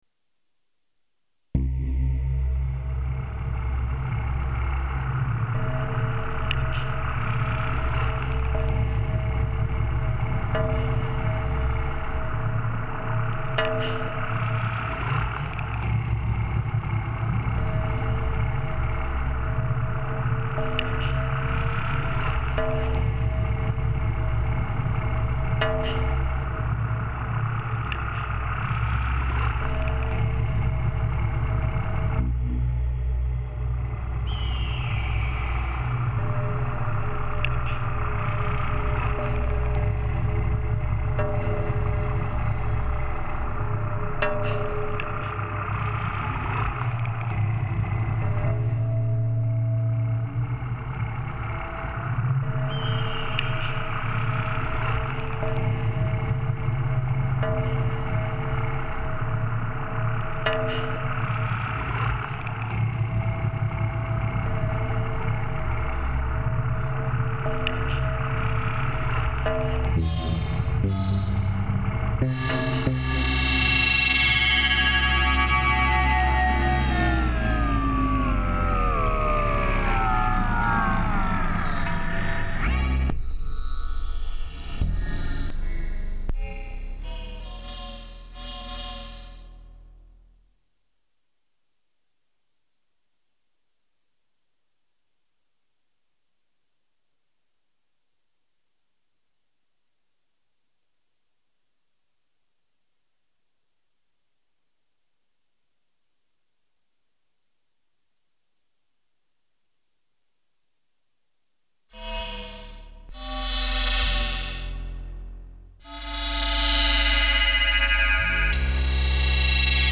I use Cakewalk Sonar, a 57 re-issue Stratocaster, DX7 (II), Precision Bass (what little bass I actually play) and Native Instruments soft synths.
Halloween Noises Here (guitars) and Here (synthesizers).